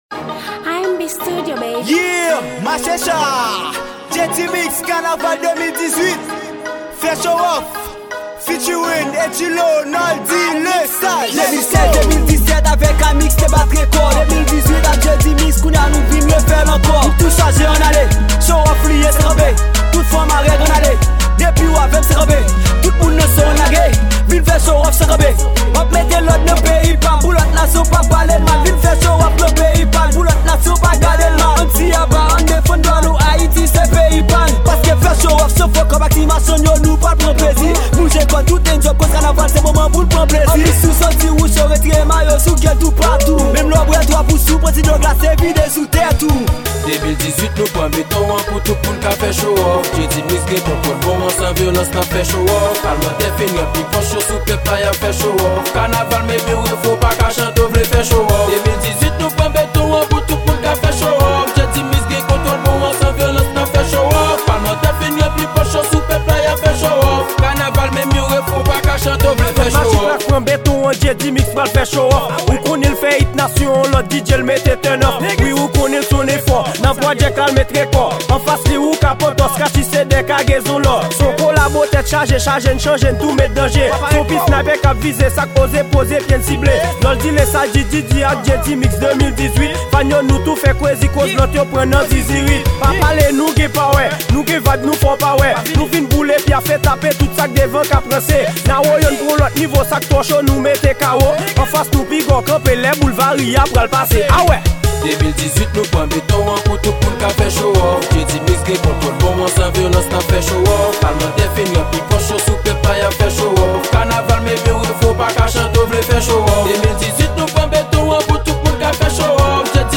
Genre : KANAVAL